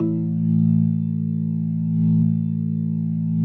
B3LESLIE A 2.wav